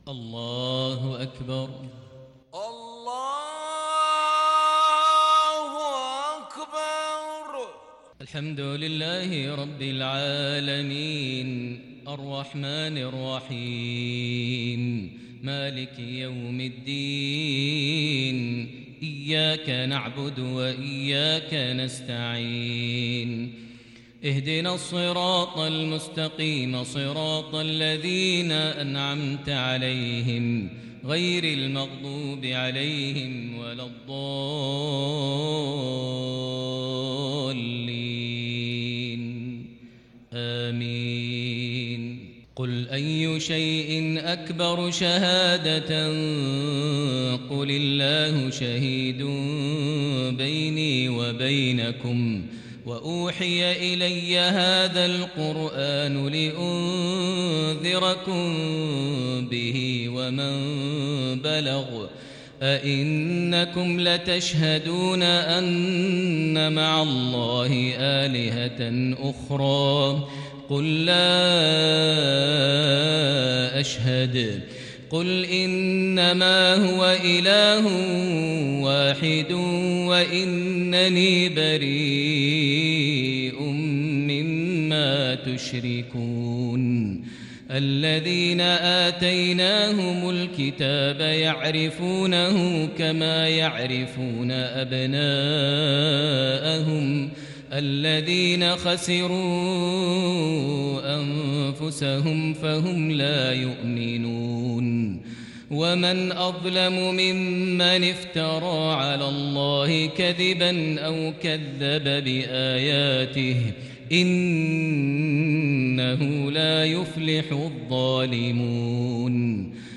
صلاة العشاء للشيخ ماهر المعيقلي 24 صفر 1442 هـ
تِلَاوَات الْحَرَمَيْن .